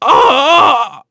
Oddy-Vox_Dead_kr.wav